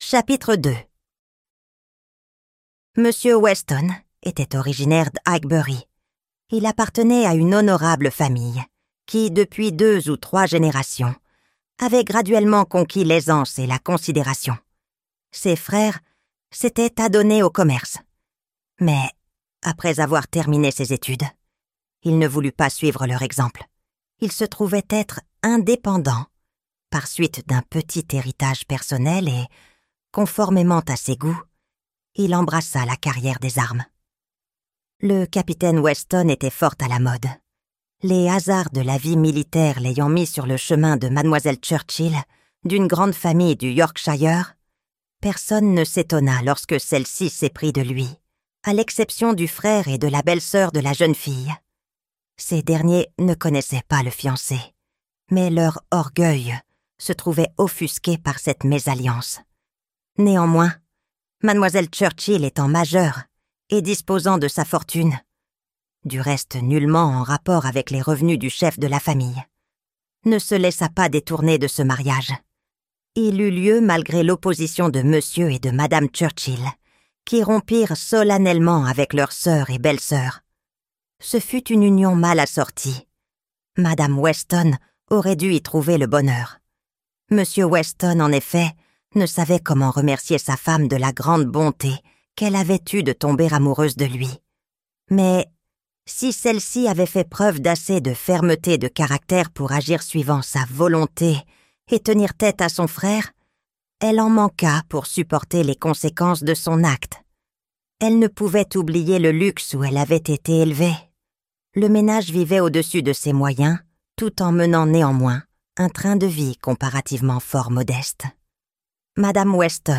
Emma - Livre Audio